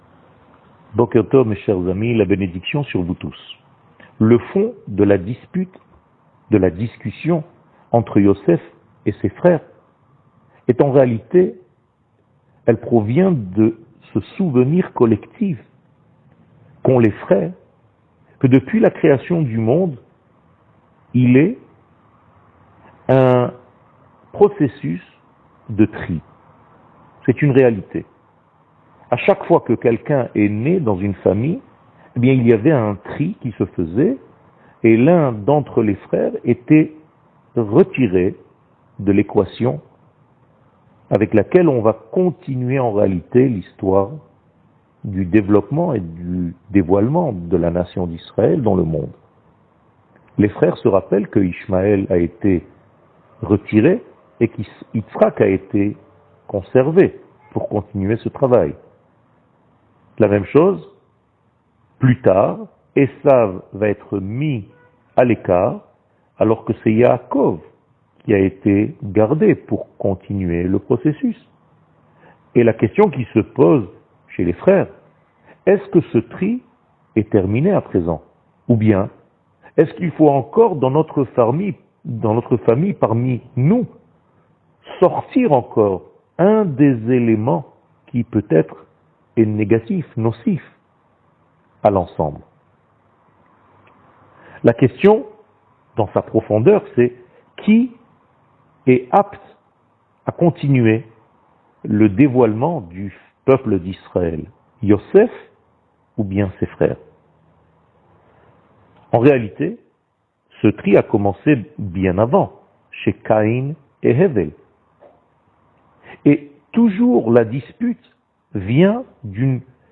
שיעור מ 25 נובמבר 2021
שיעורים קצרים